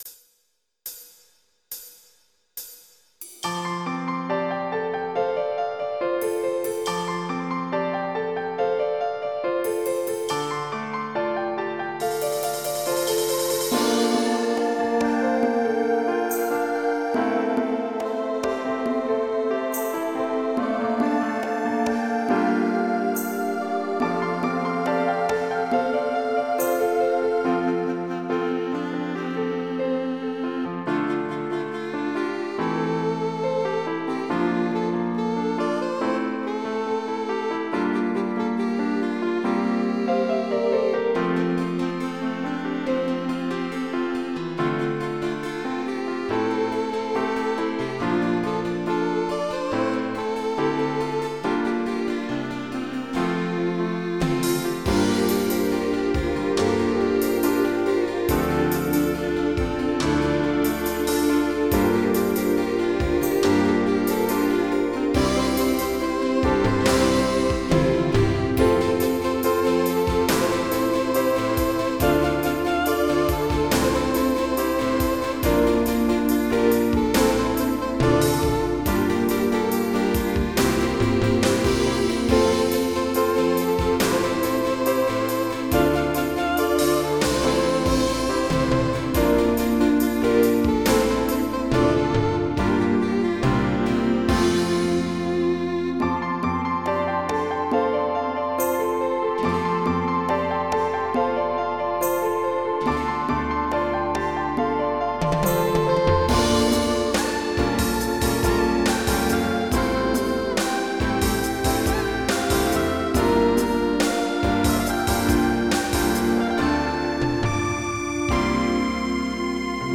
General MIDI